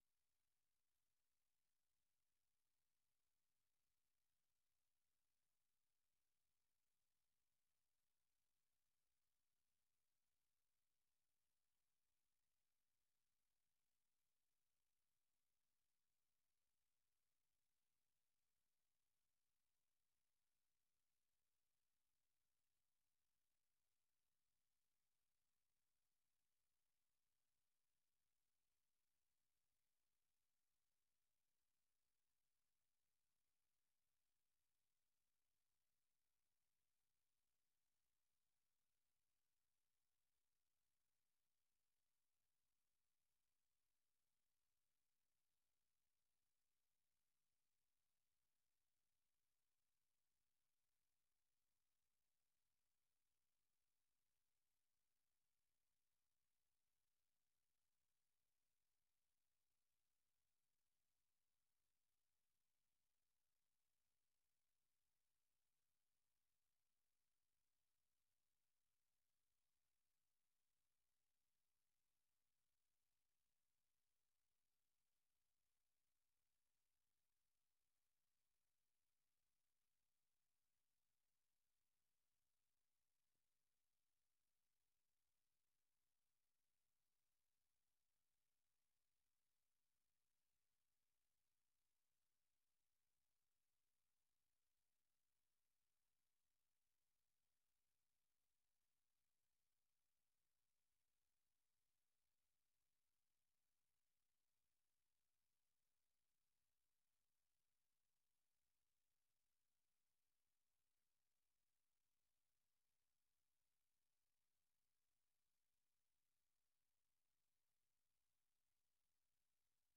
در برنامۀ صبحگاهی خبرهای تازه از افغانستان و جهان و گزارش‌های تحلیلی و مصاحبه ها در پیوند با رویدادهای داغ افغانستان و جهان به شما پیشکش می شود.